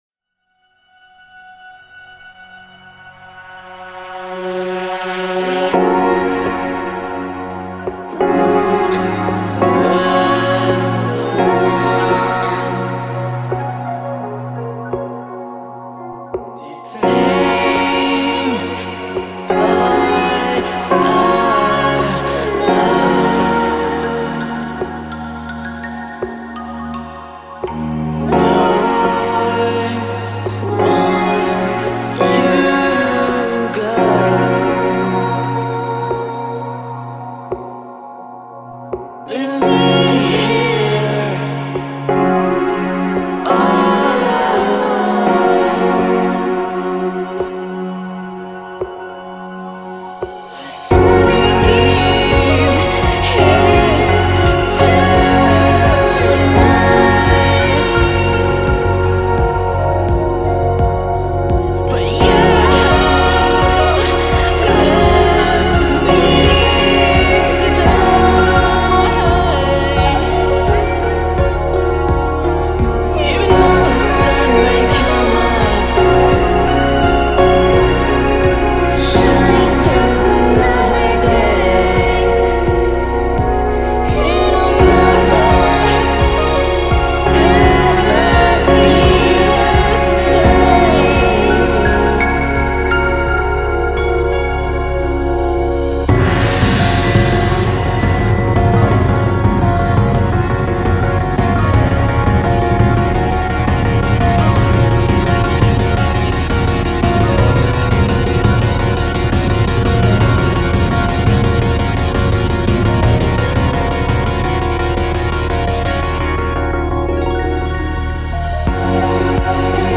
The gorgeous ending piano section
Orchestration